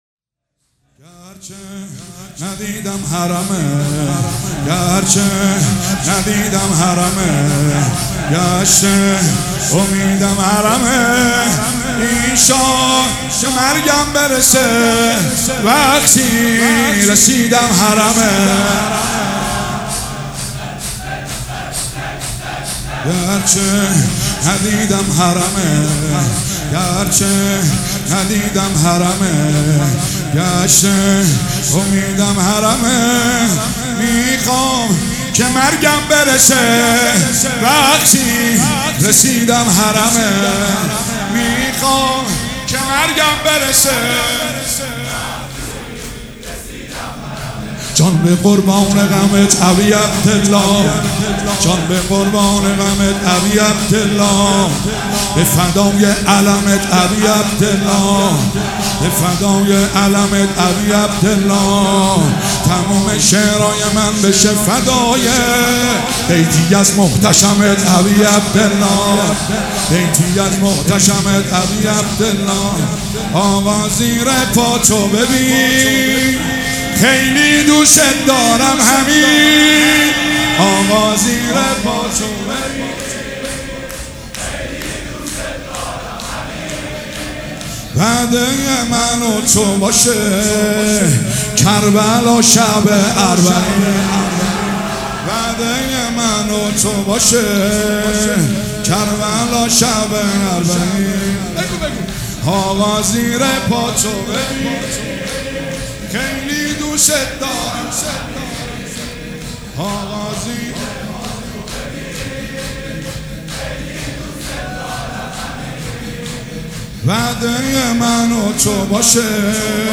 شور
مراسم عزاداری شب پنجم